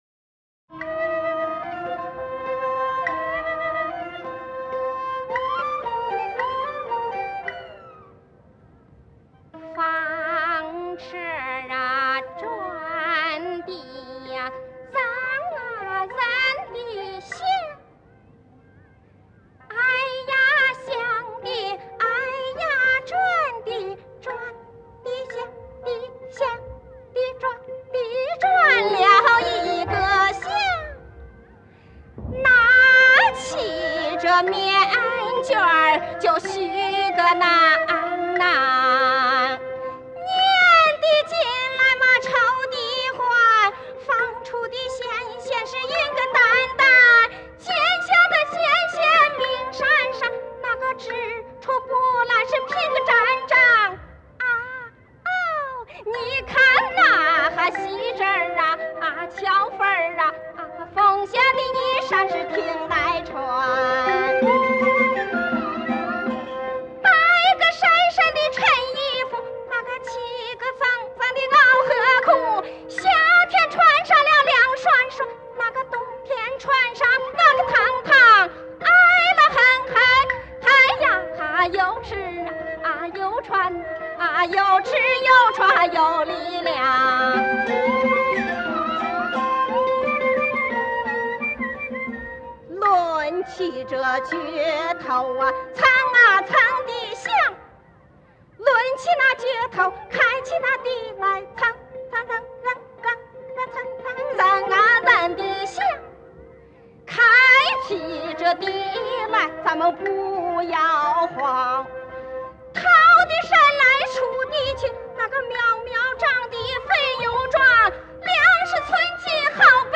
陕西民歌